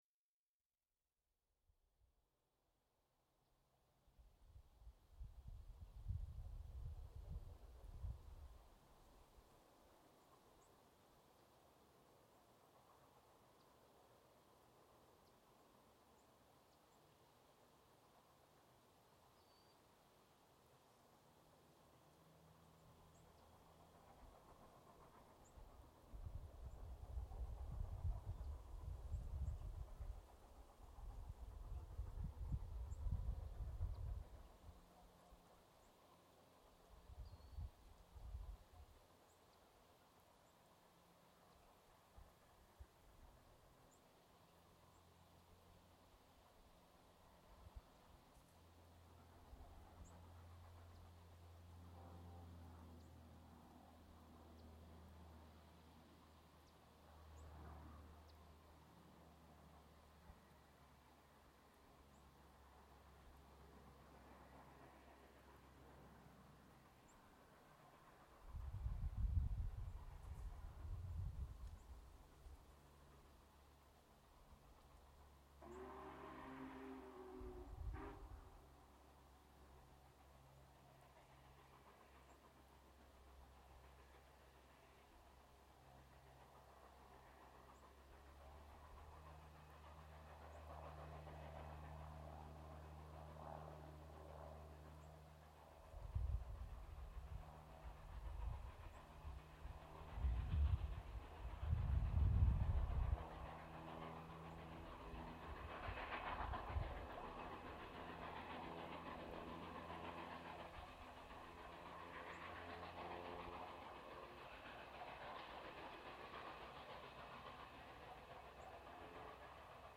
#4   Union Pacific 4014 "Big Boy" with propeller plane escort · crossing near Black Wolf, Kansas, 2024 October 19
Recorded with Tascam DR-40X on a tripod about 600 feet from the tracks.
WARNING: extreme dynamic range.
We are facing roughly northeast; the train is moving from our right to left at approximately 25 mph.
The morning was breezy enough that some buffeting is still noticeable in addition to the sound of the nearby leaves. I applied a gentle 30hz low-pass filter after the fact.